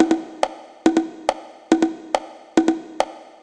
140_bongo_1.wav